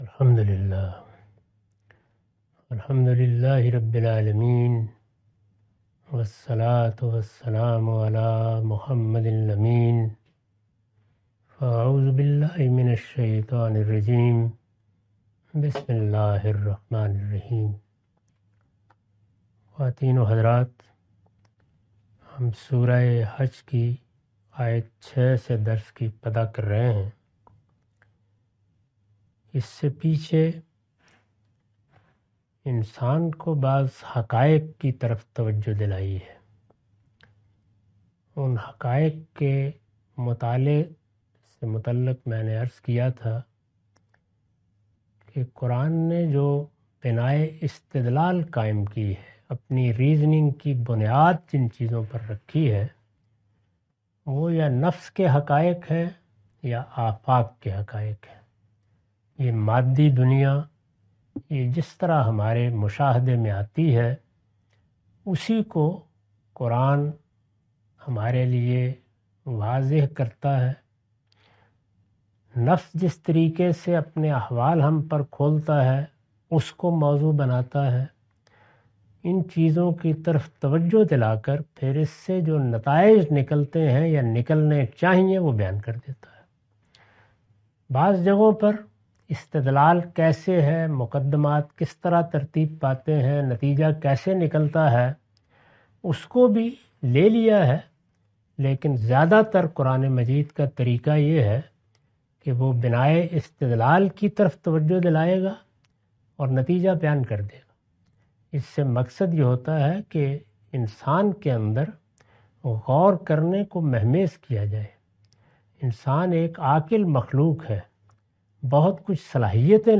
Surah Al-Hajj A lecture of Tafseer-ul-Quran – Al-Bayan by Javed Ahmad Ghamidi. Commentary and explanation of verses 06-11.